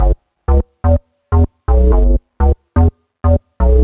cch_bass_loop_fun_125_Bm.wav